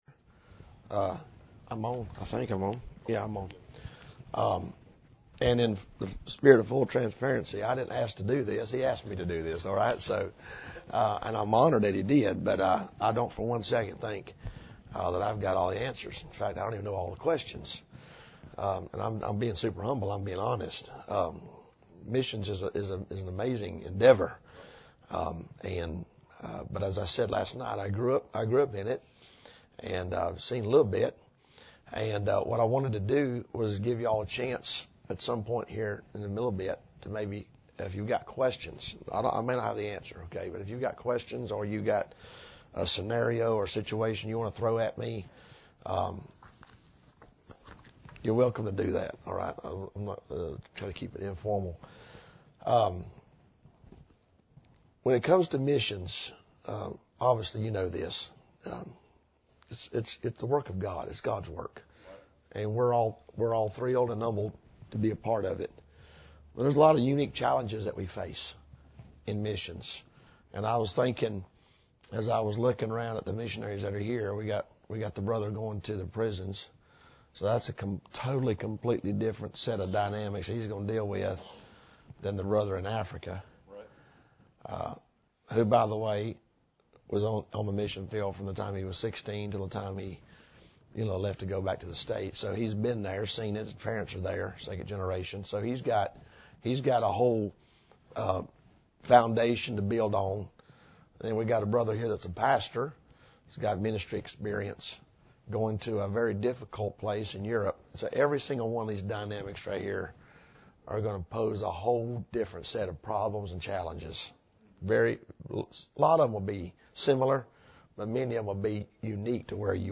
This sermon focuses on planning for the mission field: practical cautions, spiritual priorities, and steps to prepare before you go.